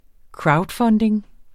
Udtale [ ˈkɹɑwdˌfʌndeŋ ]